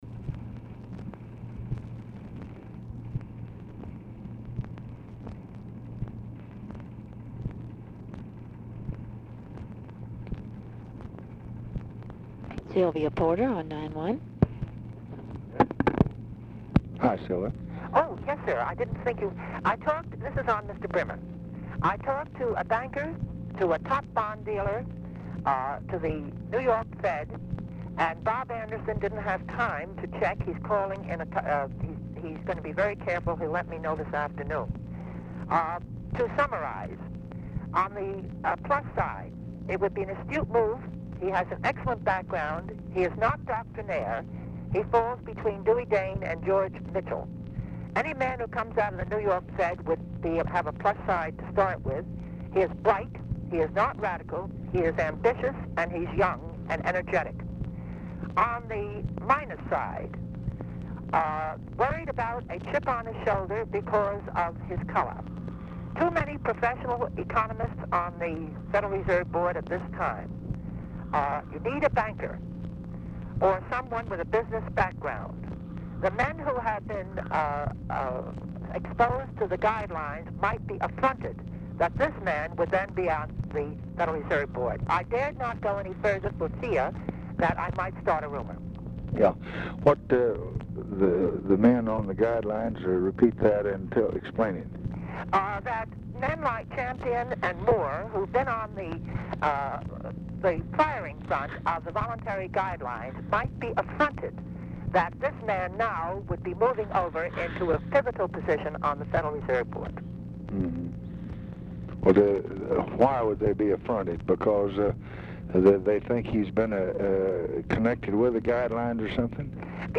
Telephone conversation # 9609, sound recording, LBJ and SYLVIA PORTER, 2/2/1966, 12:29PM | Discover LBJ
Format Dictation belt
Location Of Speaker 1 Oval Office or unknown location